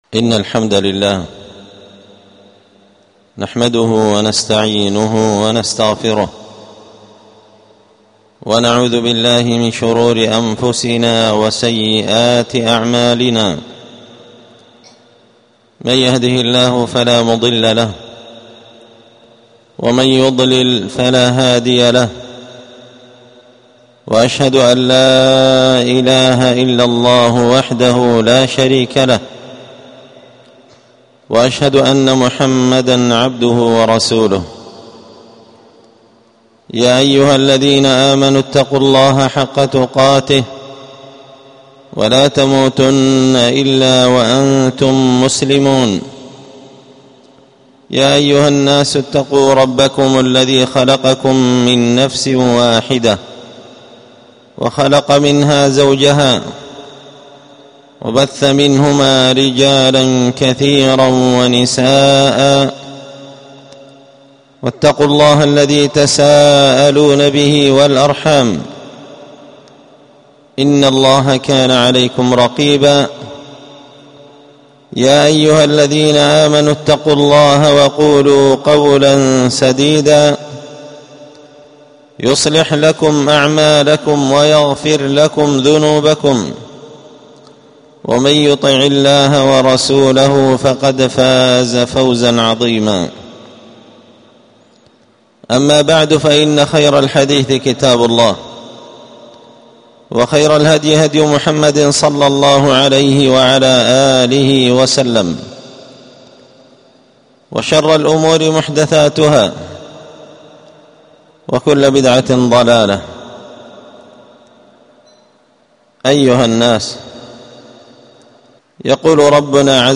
ألقيت هذه الخطبة بدار الحديث السلفية